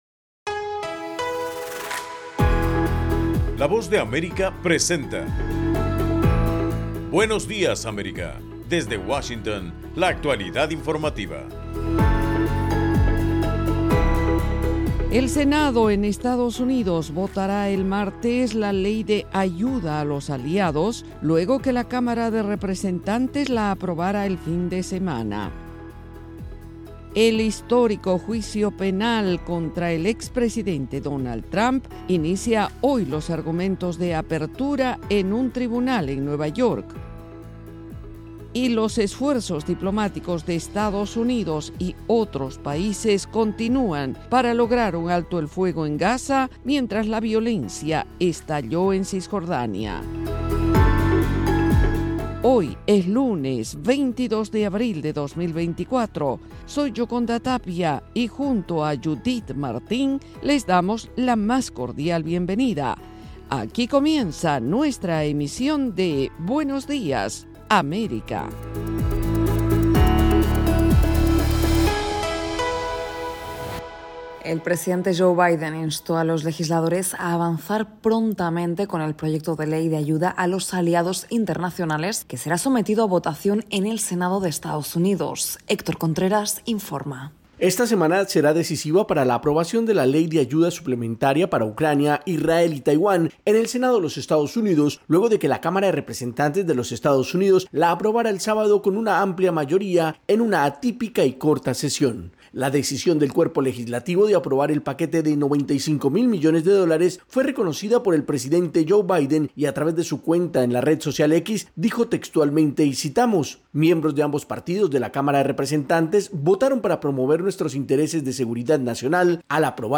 En el programa de hoy, 22 de abril, el Senado en EEUU votará el martes la ley de ayuda a los aliados luego que la Cámara de Representantes la aprobará el fin de semana. Esta y otras noticias de Estados Unidos y América Latina en Buenos Días América, un programa de la Voz de América.